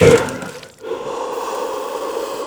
BREATH    -R.wav